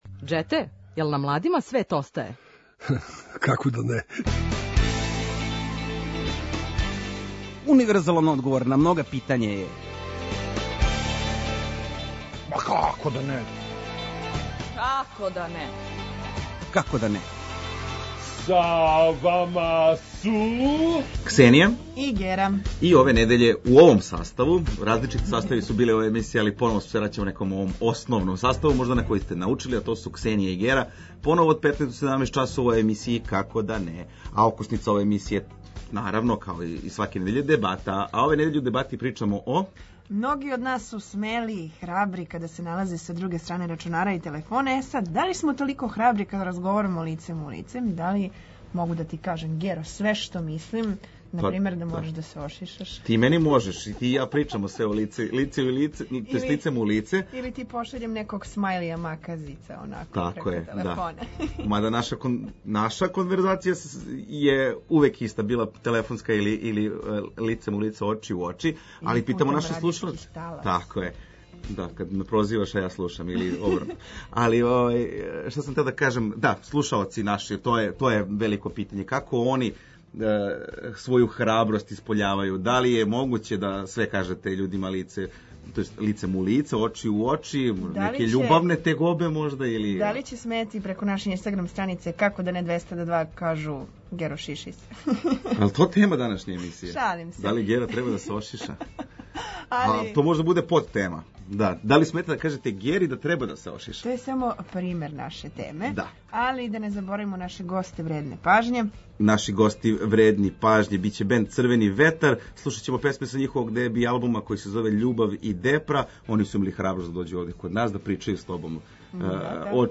Oкосница емисије је „Дебата” у којој ћемо разменити мишљења о различитим темама и дилемама.